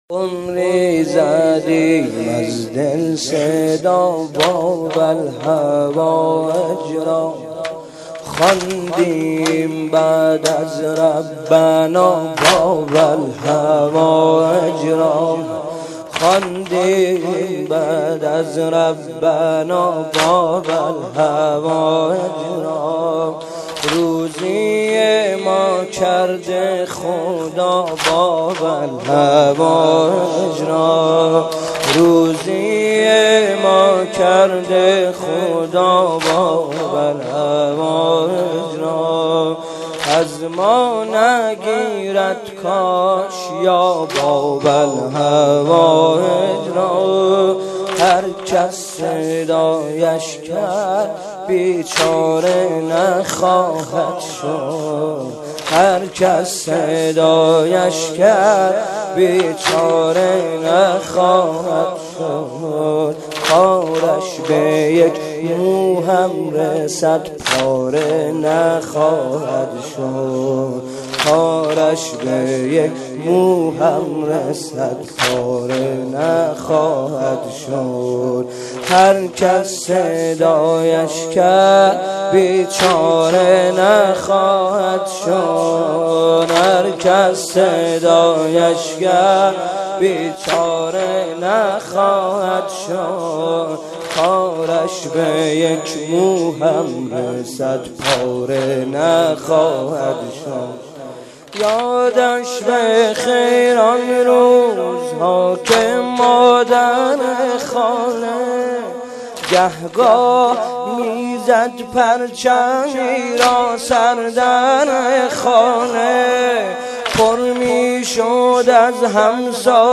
شب هفتم رمضان95، حاج محمدرضا طاهری